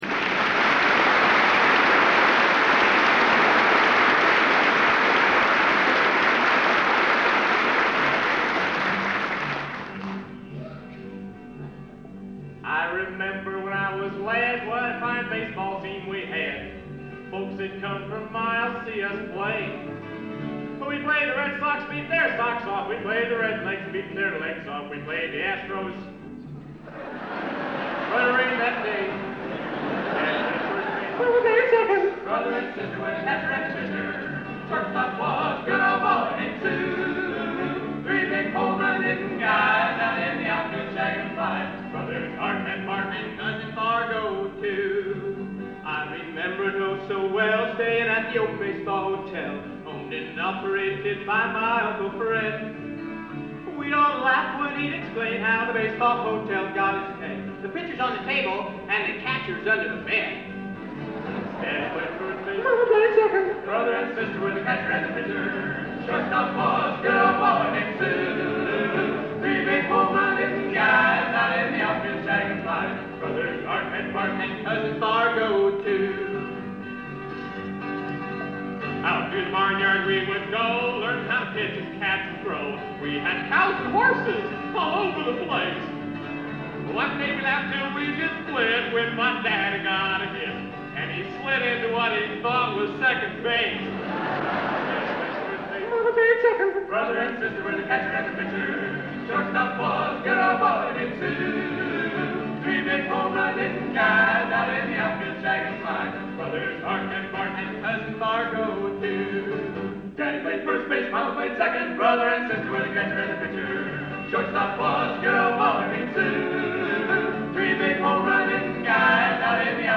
Trio.